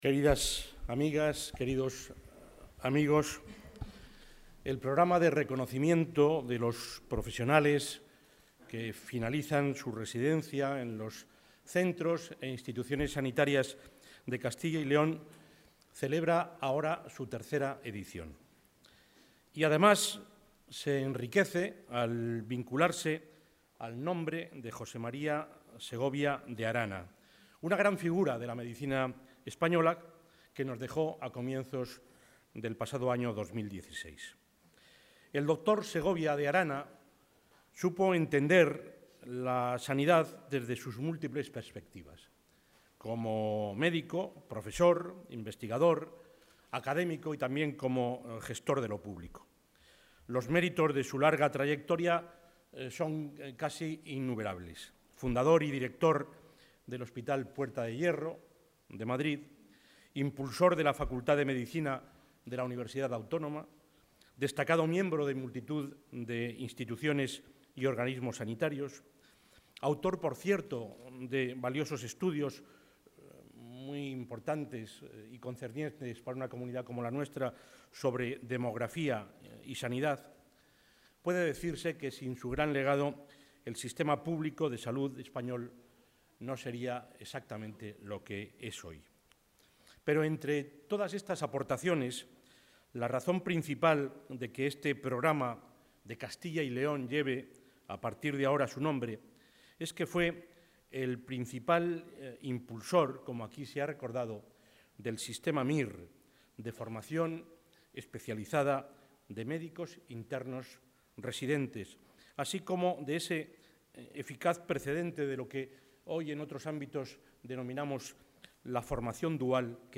Intervención de Juan Vicente Herrera.
El presidente de la Junta, Juan Vicente Herrera, ha entregado esta mañana los ‘Premios Jose María Segovia de Arana’, con los que se reconoce a los profesionales que finalizan su residencia en los centros asistenciales de Castilla y León.